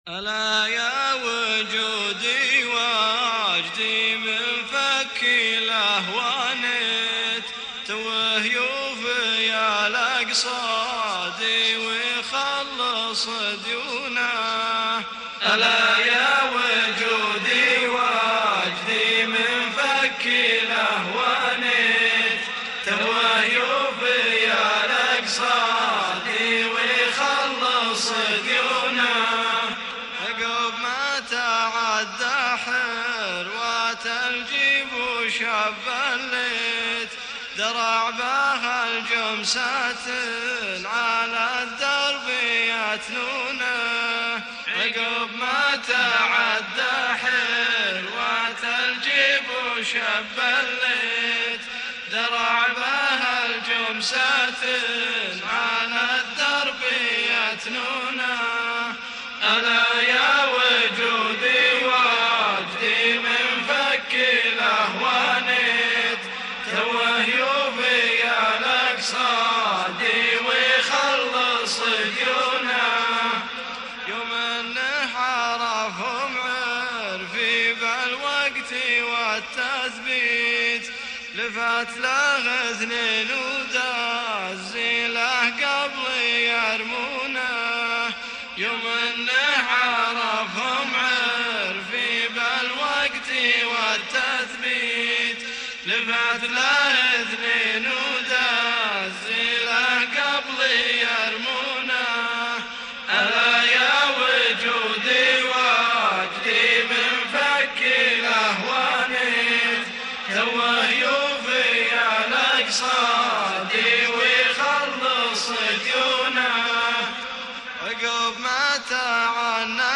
( لون شعبي)
وبمشاركة احد الأخوه في( الكورس البشري )
هندسة صوت
وبإعادة المونتاج للمادة .